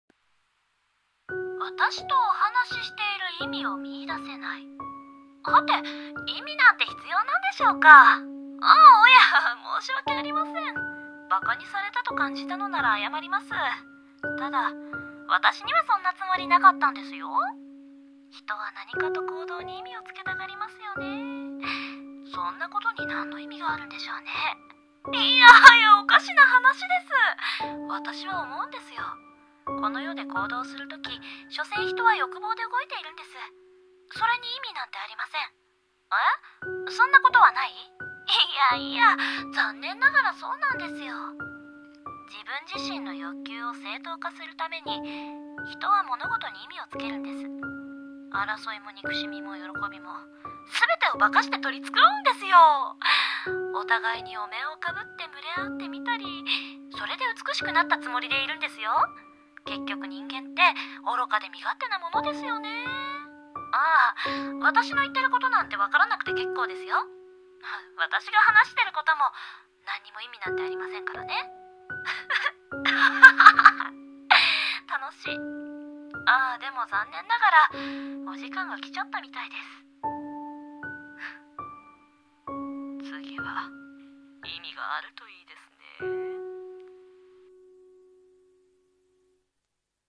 【声劇】所詮そんなモノです。